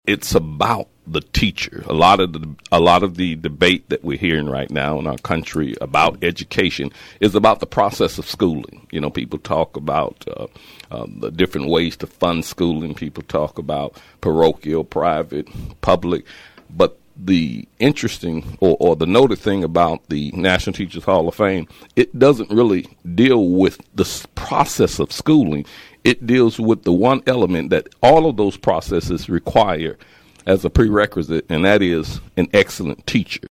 KVOE Morning Show interview